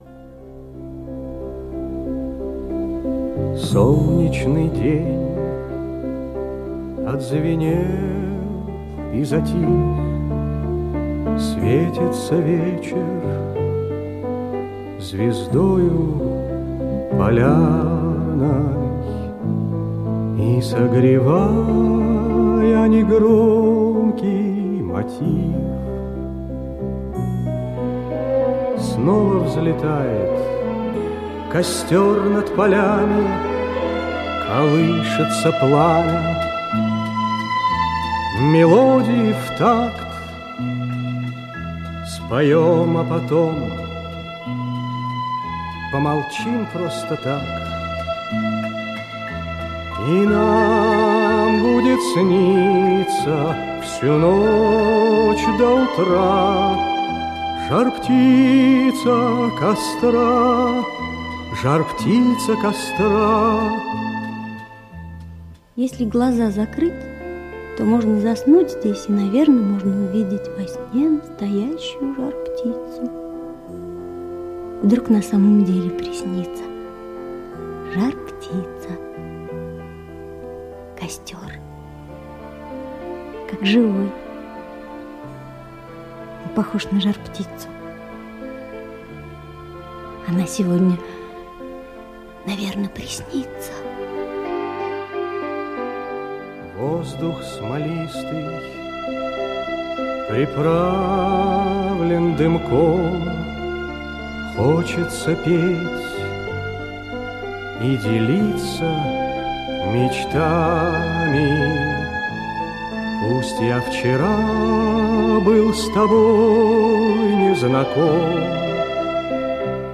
Оруженосец Кашка - аудио повесть Крапивина - слушать онлайн